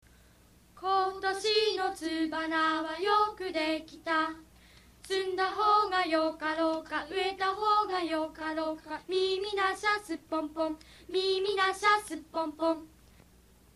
うた